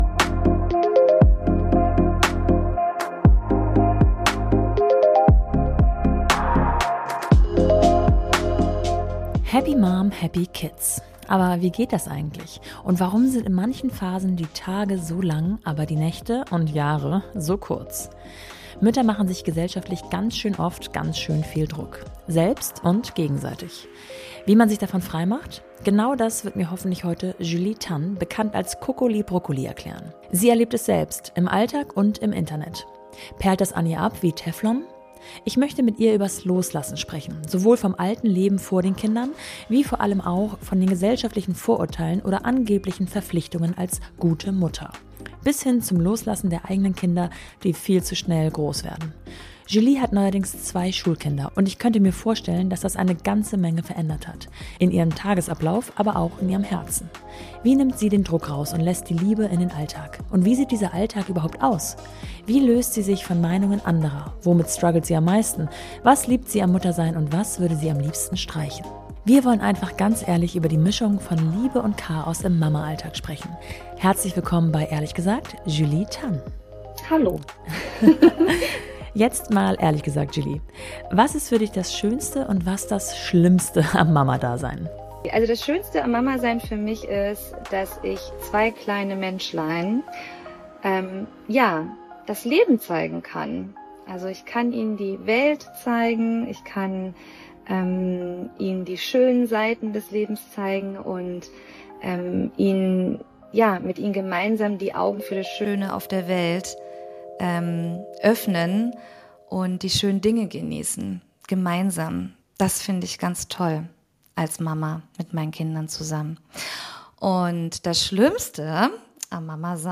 Ein ehrliches Gespräch über das Loslassen alter Rollen, den Mut, authentisch zu bleiben und die Kraft der Community.